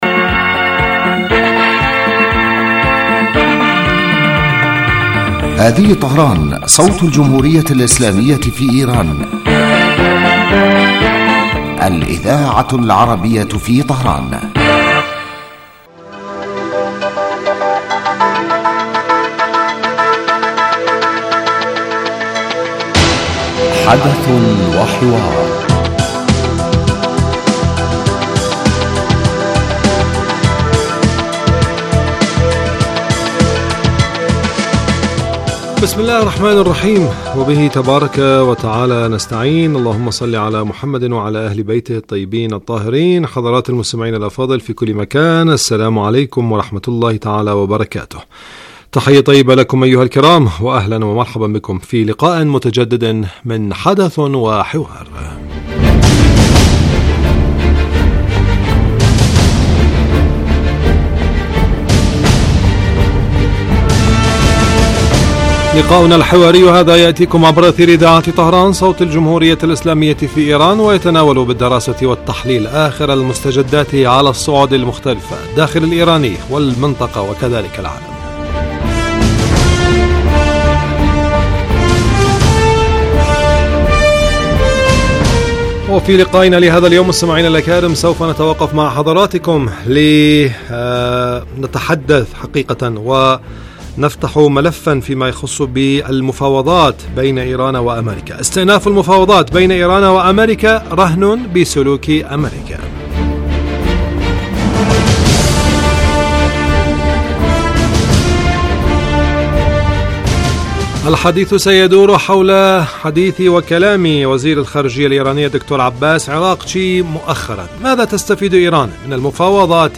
يبدأ البرنامج بمقدمة يتناول فيها المقدم الموضوع ثم يطرحه للنقاش من خلال تساؤلات يوجهها للخبير السياسي الضيف في الاستوديو.
ثم يتم تلقي مداخلات من المستمعين هاتفيا حول الرؤى التي يطرحها ضيف الاستوديو وخبير آخر يتم استقباله عبر الهاتف ويتناول الموضوع بصورة تحليلية.